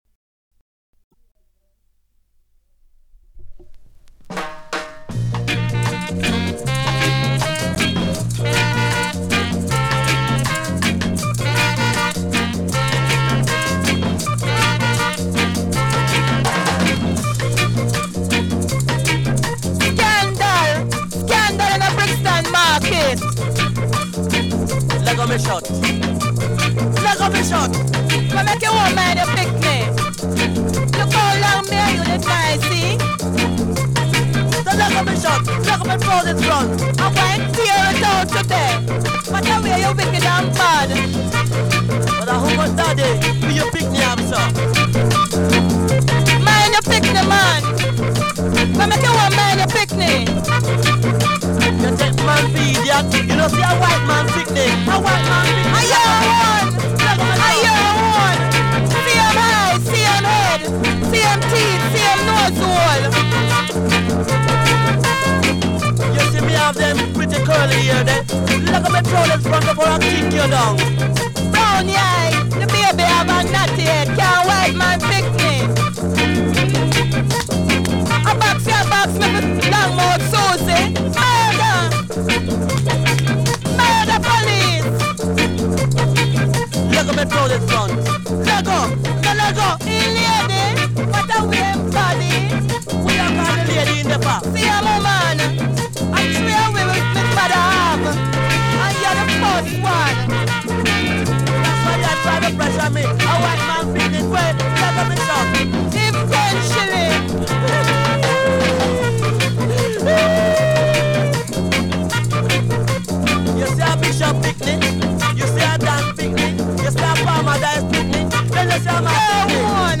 ITW Bourse Vinyles
ITW_Bourse_Vinyles.mp3